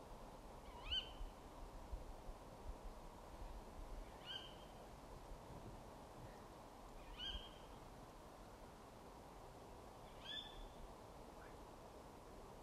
Opptaket er gjort i ein nærskog ca kl 22, 11. feb.
Dette er lyd fra en kattugle hunn.
rar_lyd_i_skogen.m4a